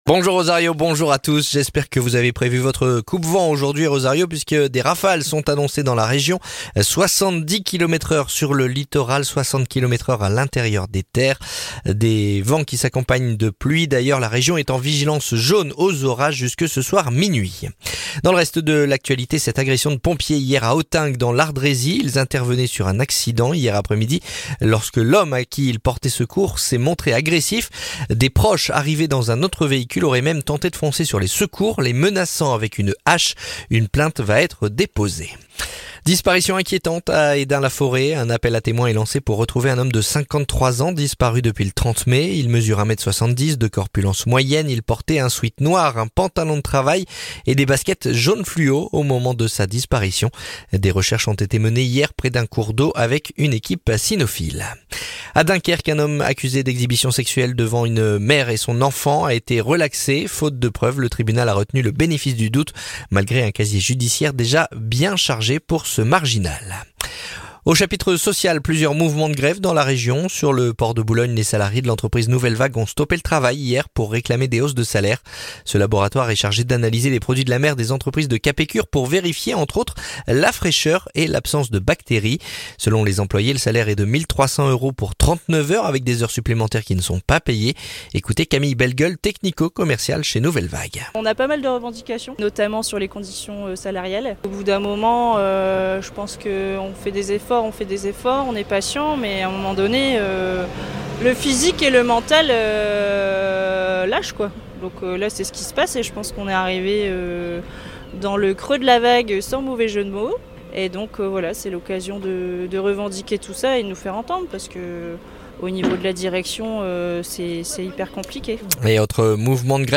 Le journal du samedi 7 juin